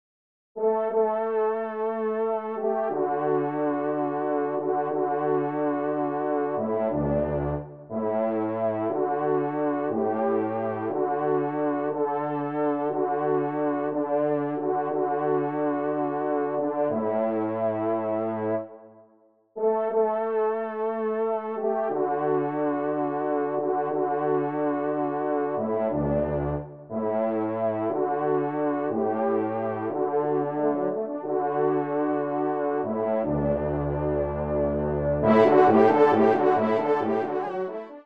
Genre : Musique Religieuse pour  Quatre Trompes ou Cors
Pupitre 4° Trompe